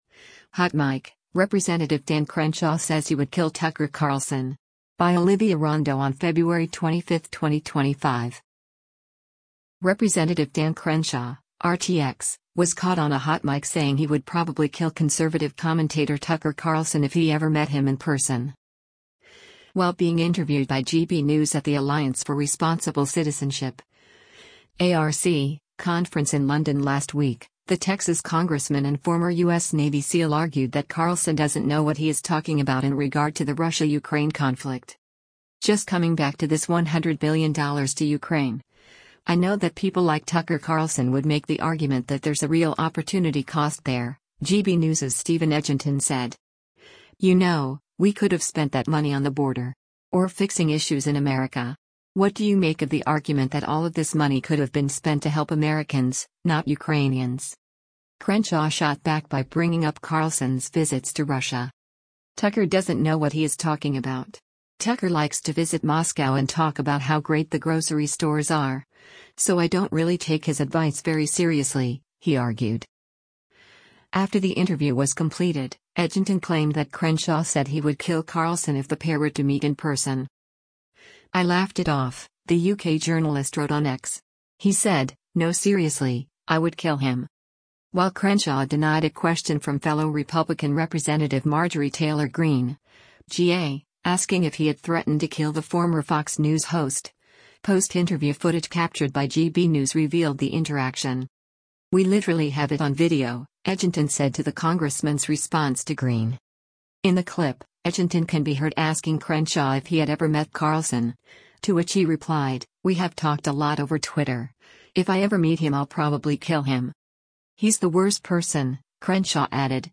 Rep. Dan Crenshaw (R-TX) was caught on a hot mic saying he would “probably kill” conservative commentator Tucker Carlson if he ever met him in person.